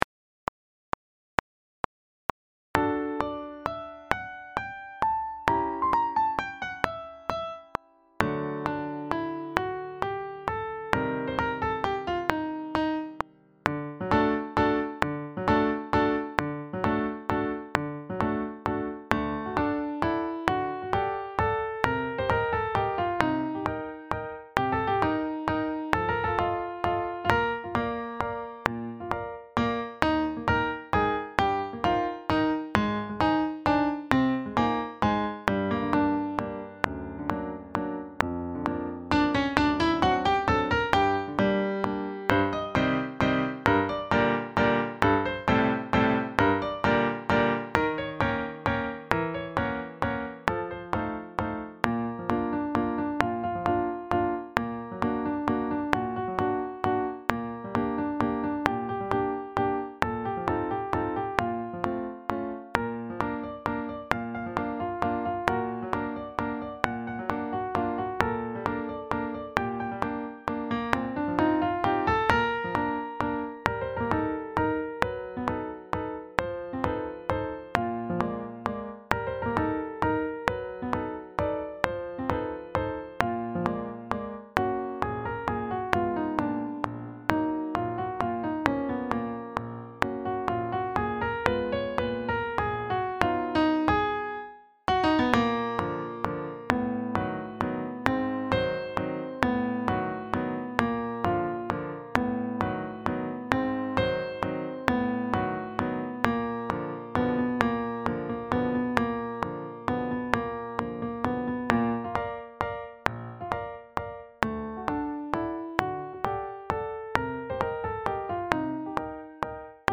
Sax Septets
Here we make use of his arrangement but recast it for saxophone sextet with the solo on alto sax, a perfect fit for its playful and seductive nature.
Backing track
170-4-seguidilla-backing-track.mp3